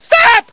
almost finished mining laser and lots of screaming scientists
scream12.ogg